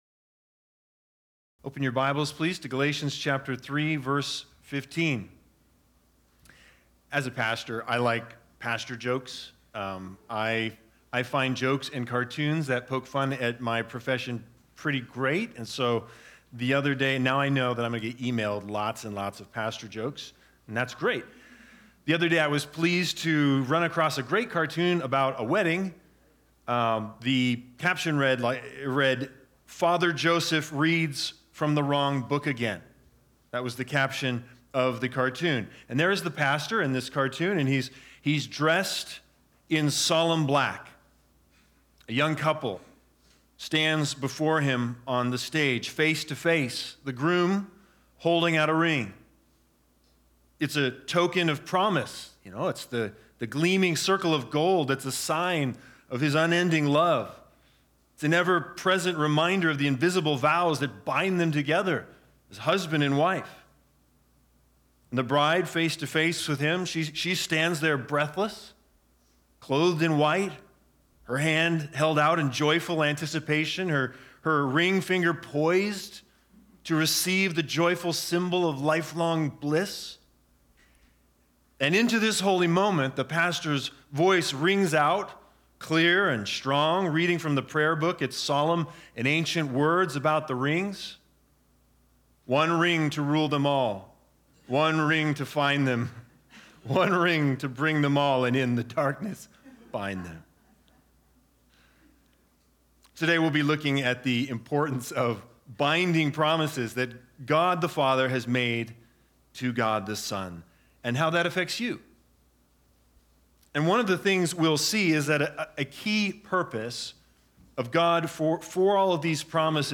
Galatians 3:15-20 Service Type: Sunday Sermons BIG IDEA